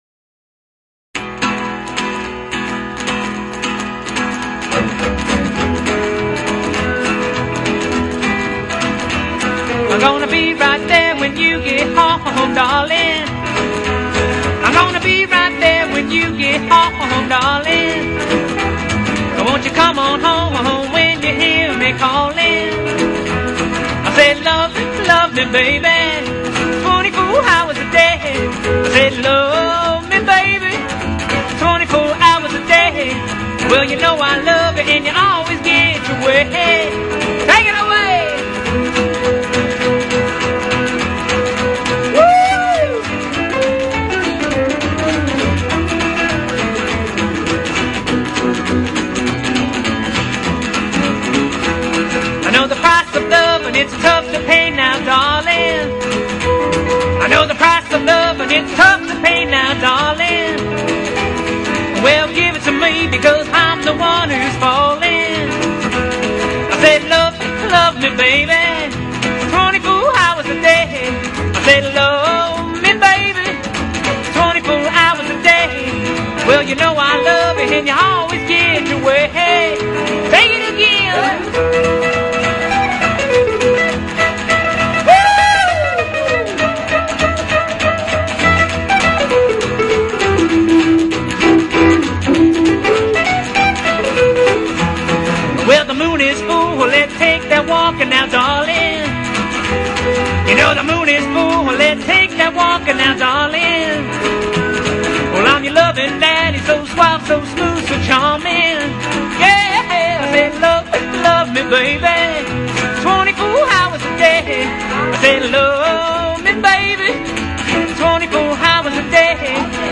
Authentic Hillbilly, Rockabilly, and Rock 'N Roll tracks